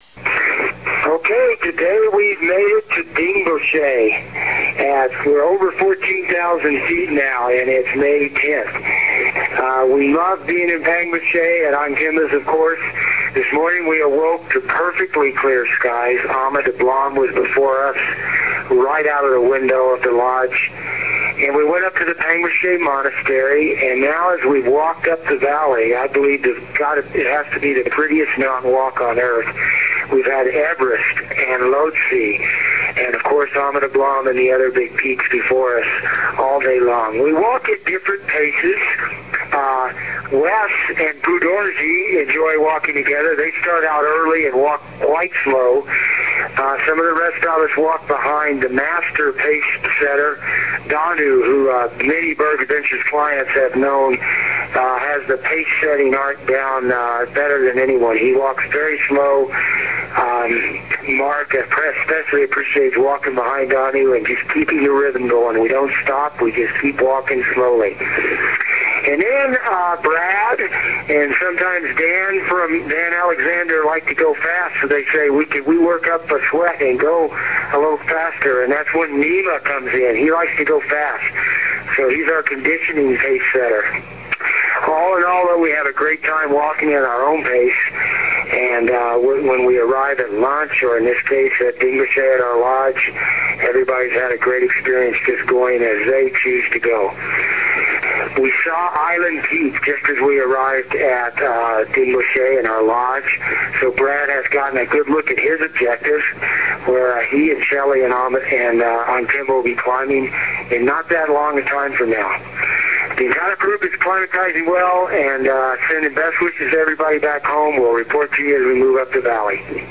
Berg Adventures International: Everest Base Camp Expedition Cybercast